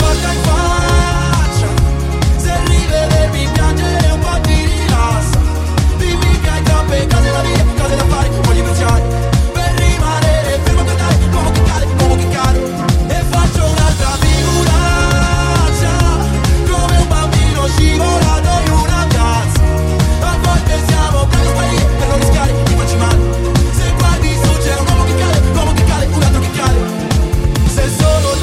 Genere: italiana,sanremo2026,pop.ballads,rap,hit